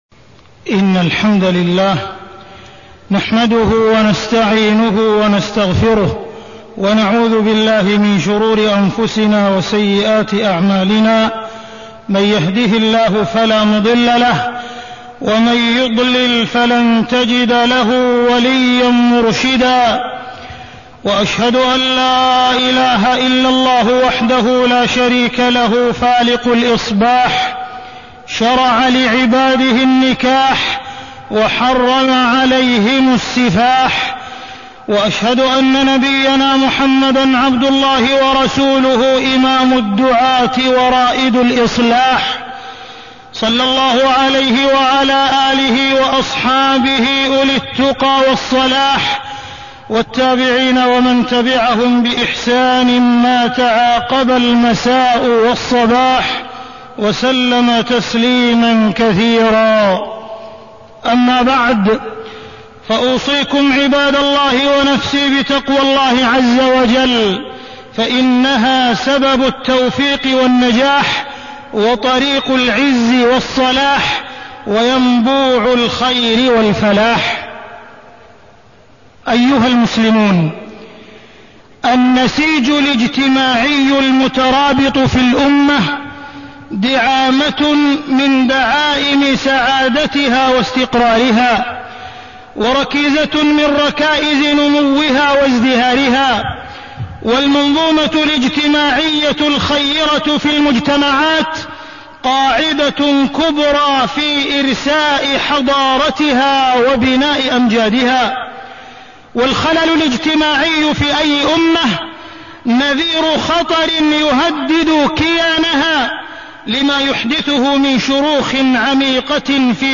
تاريخ النشر ٩ جمادى الأولى ١٤٢٣ هـ المكان: المسجد الحرام الشيخ: معالي الشيخ أ.د. عبدالرحمن بن عبدالعزيز السديس معالي الشيخ أ.د. عبدالرحمن بن عبدالعزيز السديس العزوبة والعنوسة